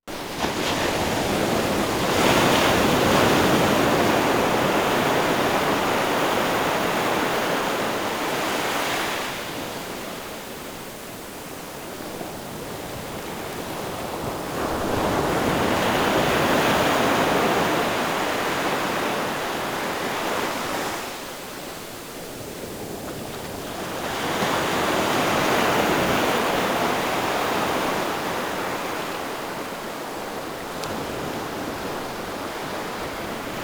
WORLD SOUNDSCAPE PROJECT TAPE LIBRARY
China Beach, ocean waves 0:34